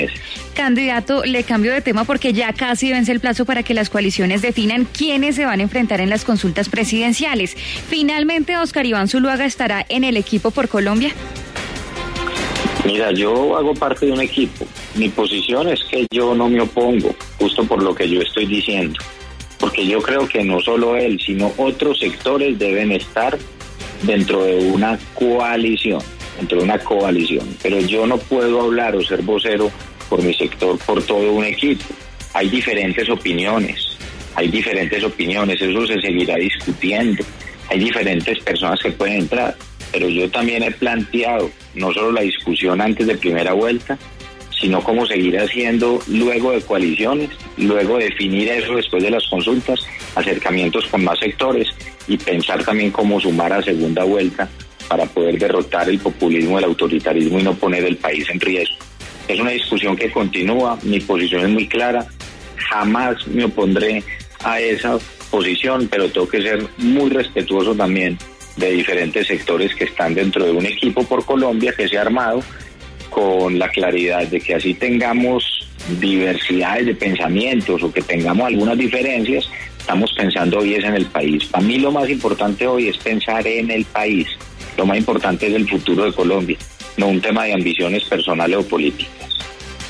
En diálogo con La W, el candidato presidencial Federico Gutiérrez habló sobre la posible llegada de Óscar Iván Zuluaga al Equipo por Colombia.